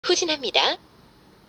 reverse_gear.wav